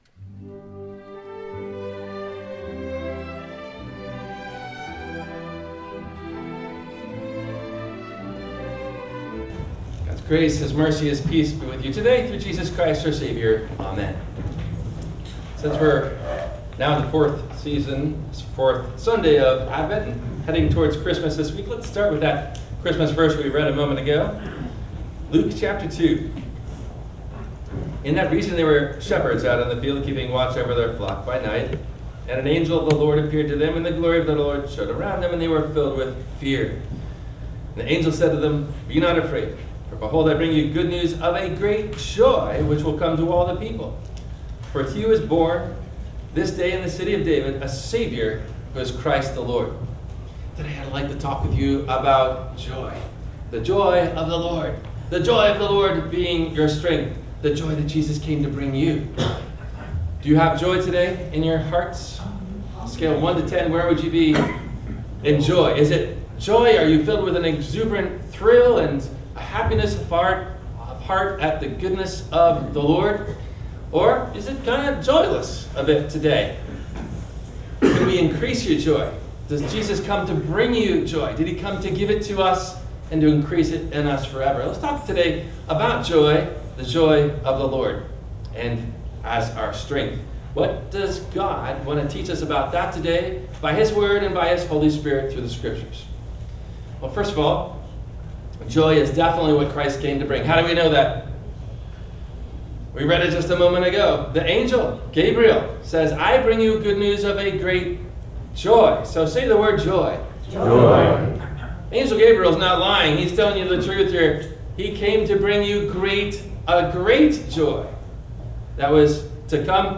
Does Jesus Bring Us … Joy? – WMIE Radio Sermon – December 29 2025